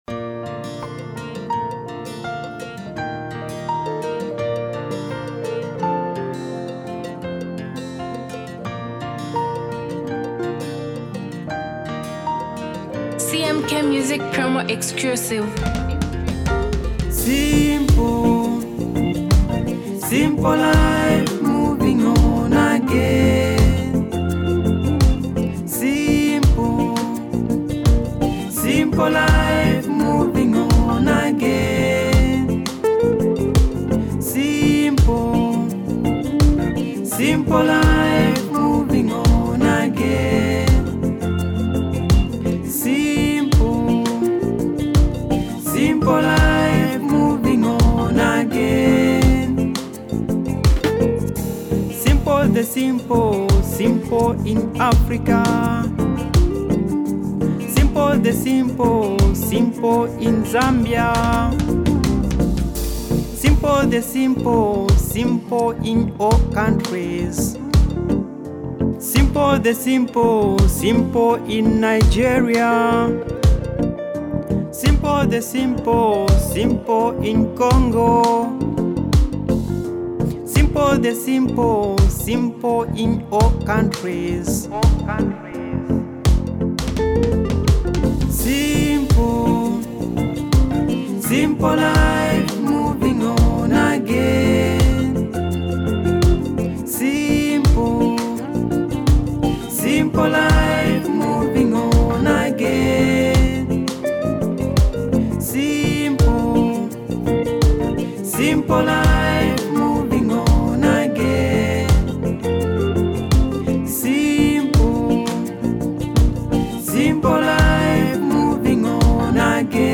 With a soothing melody
With its catchy rhythm and thought-provoking lyrics